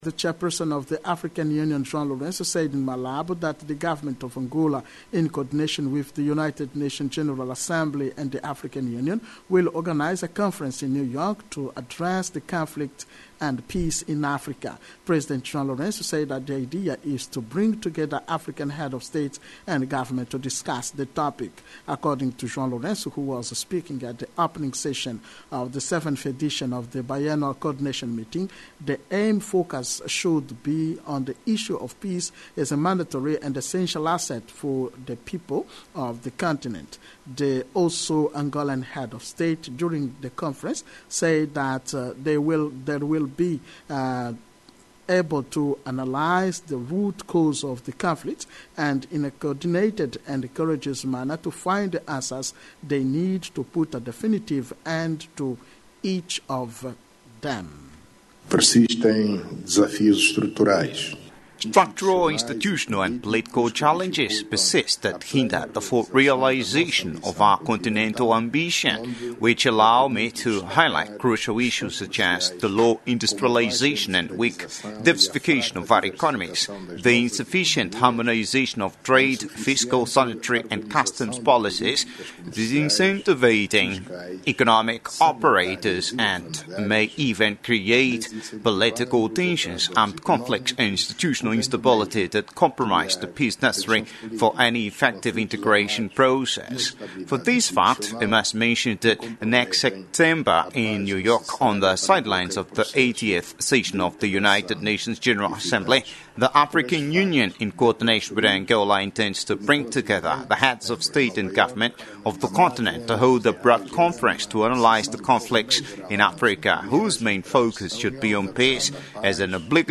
According to Joao Lourenço, who was speaking at the opening session of the 7th edition of the Biennual Coordination Meeting, the main focus should be on the issue of peace as a mandatory and essential asset for the people of the continent.